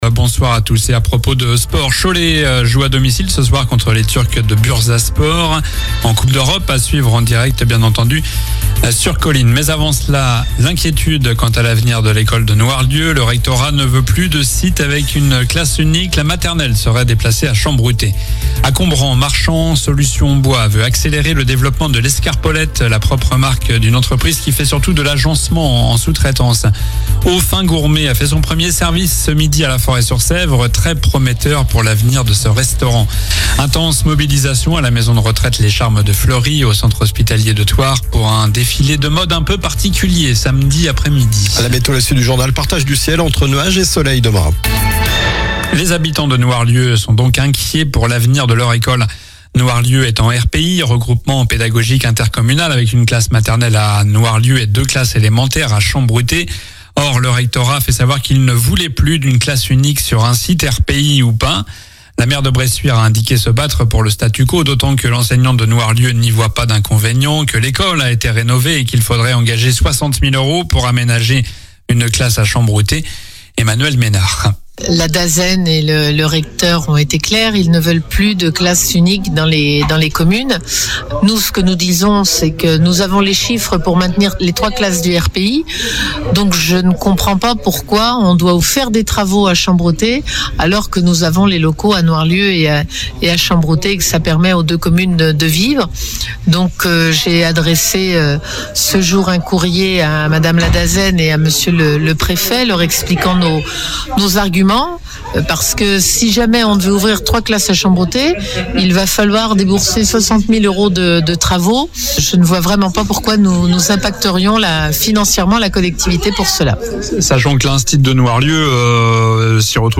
Journal du mercredi 19 novembre (soir)